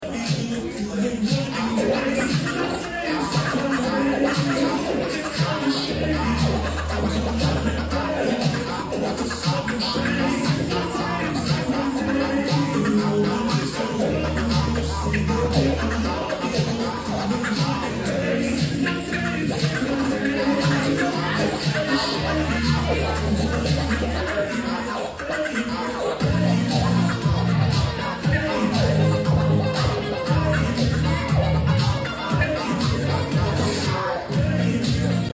Warm Up Cuts Before Set Sound Effects Free Download